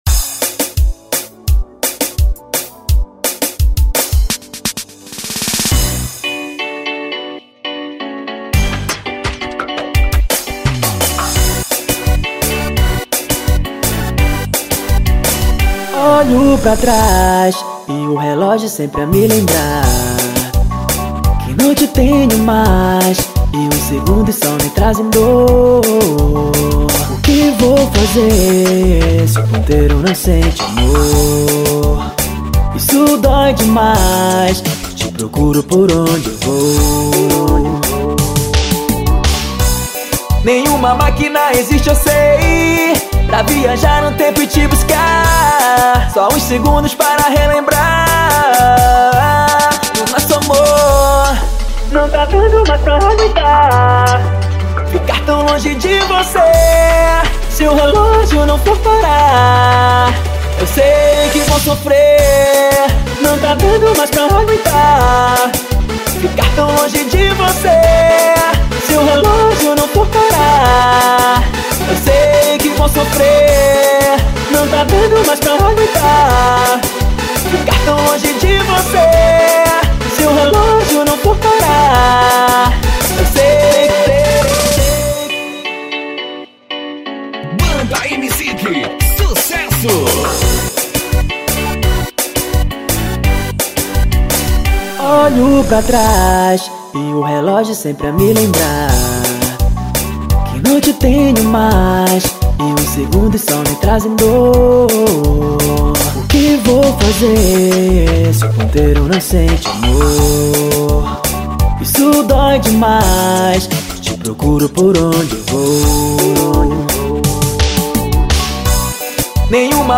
OUÇA NO YOUTUBE Labels: Melody Facebook Twitter